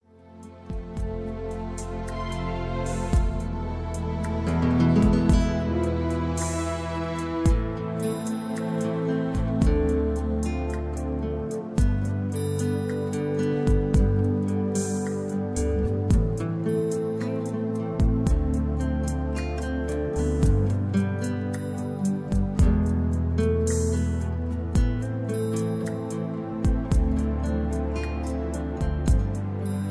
(Key-Am, Tono de Am) Karaoke MP3 Backing Tracks